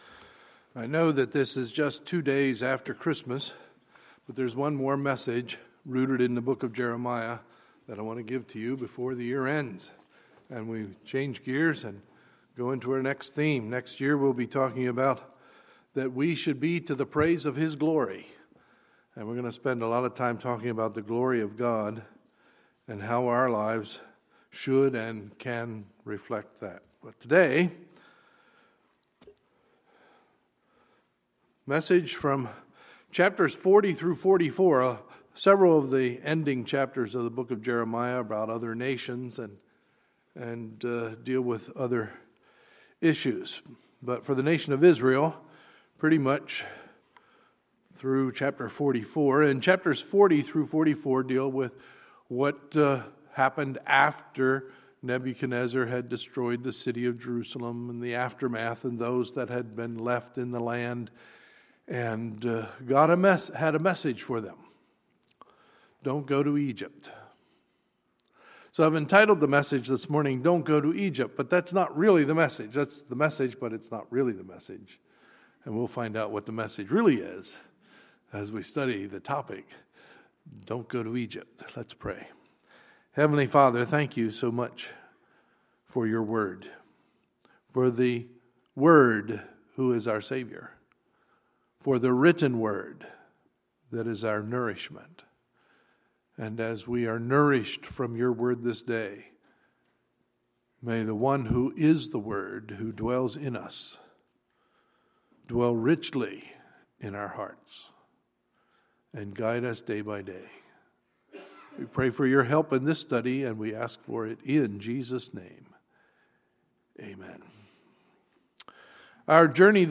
Sunday, December 27, 2015 – Sunday Morning Service